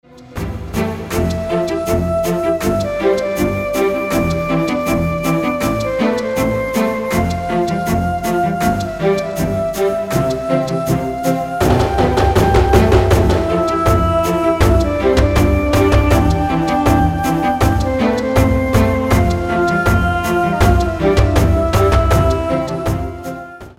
Muzyka o charakterze muzyki filmowej.